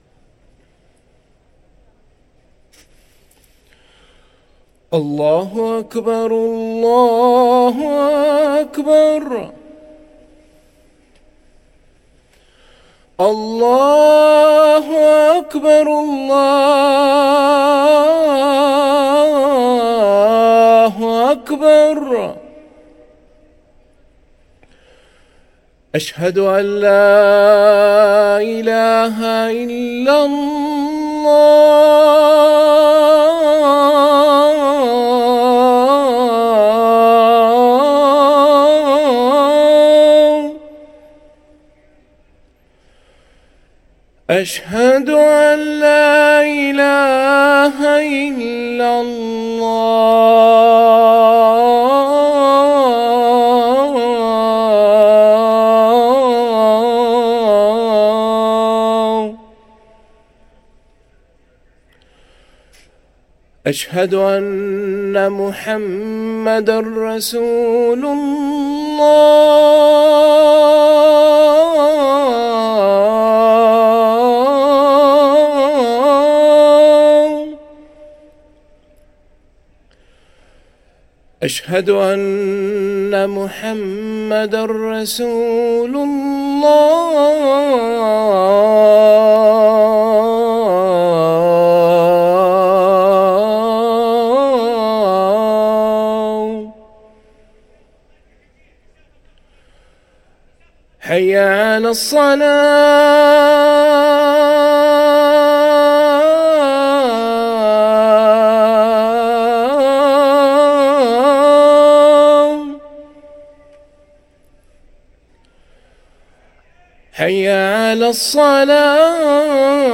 أذان الفجر